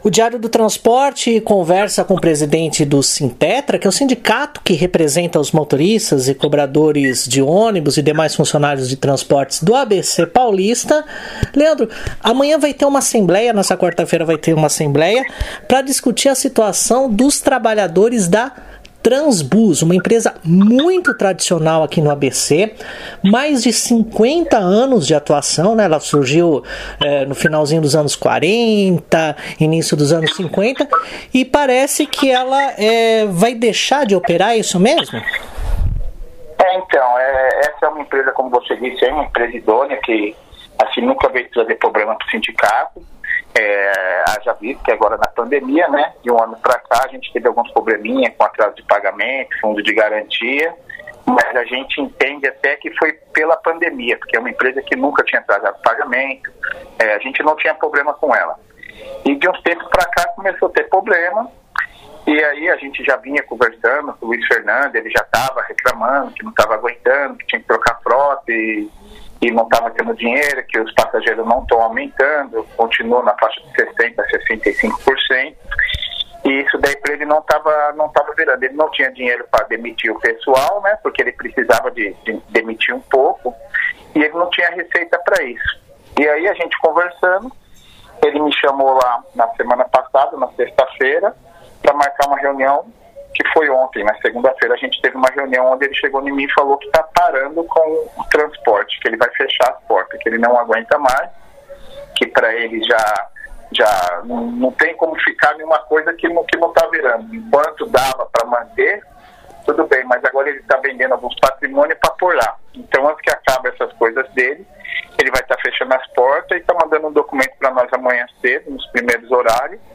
ENTREVISTA: Trans-Bus, no ABC, anuncia intenção de encerramento de atividades, diz Sindicato